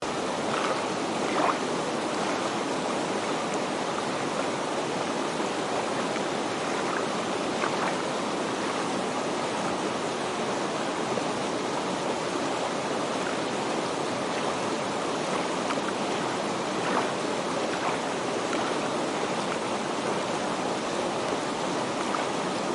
Slapshing Down The Rapids
Slapshing Down The Rapids is a free nature sound effect available for download in MP3 format.